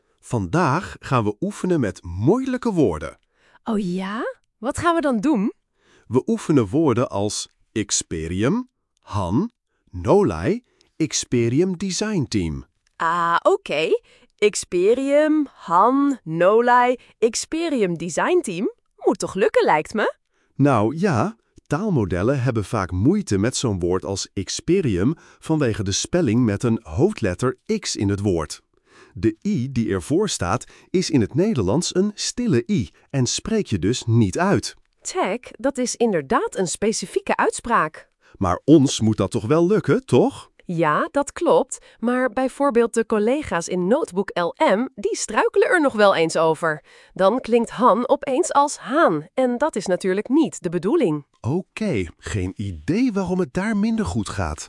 Ik had een tweede test gemaakt waarbij specifiek woorden als iXperium, HAN, NOLAI, iXperiumdesignteam getest werden. Vreemd genoeg ging het een paar keer goed (ik was aan het experimenteren met verschillende stemmen), daarna werd HAN tot twee keer toe wél weer HAAN.
HAN-of-HAAN.mp3